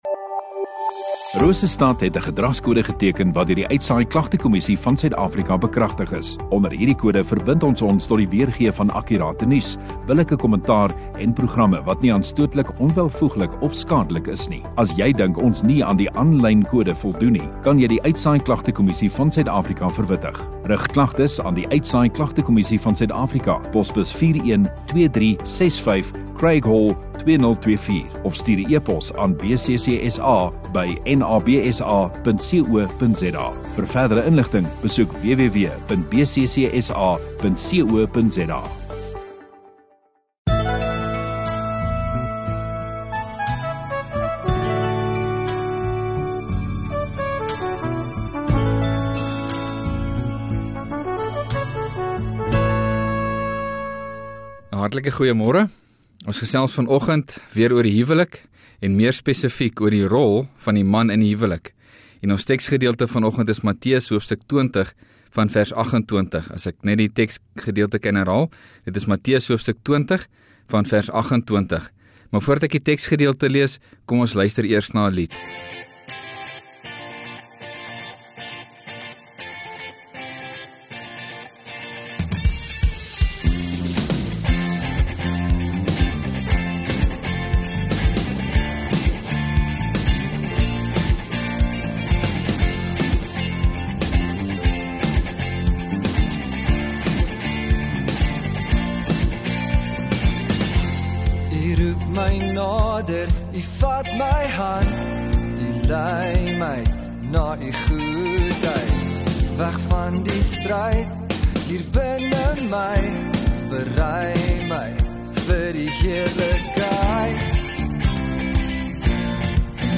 16 Nov Saterdag Oggenddiens